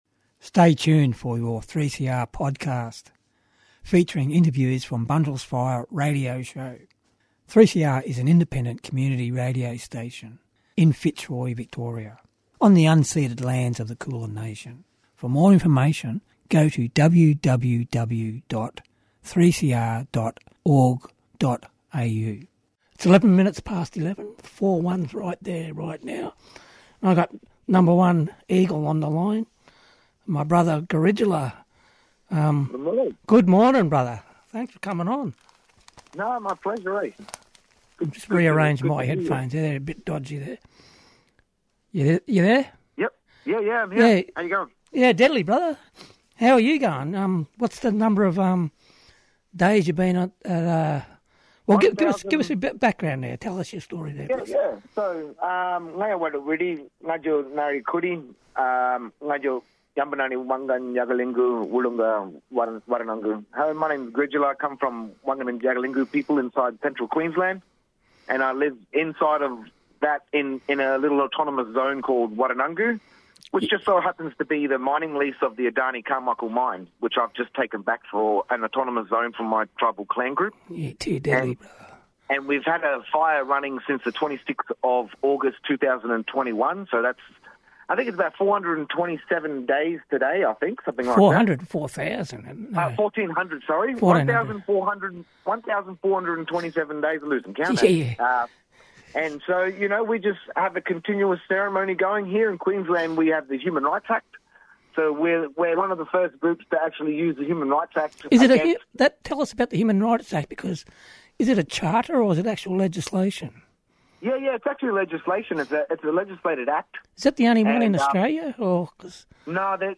Featuring the best of blak music.